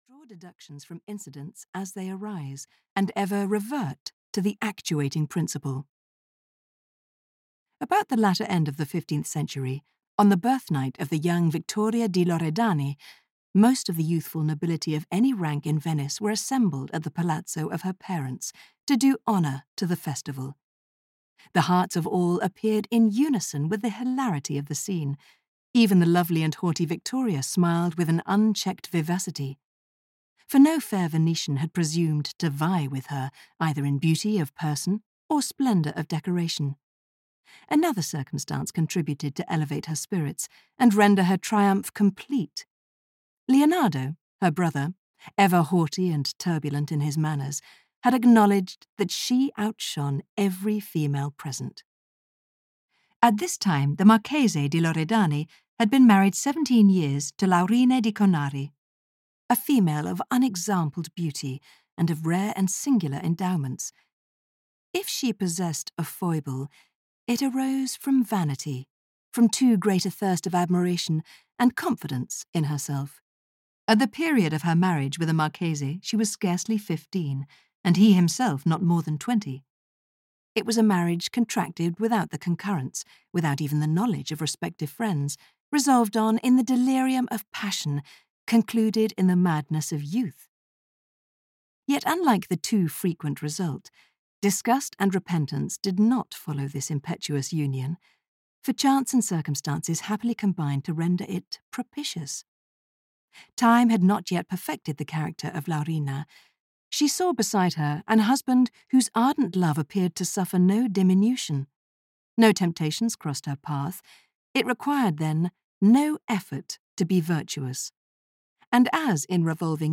Zofloya, or The Moor (EN) audiokniha
Ukázka z knihy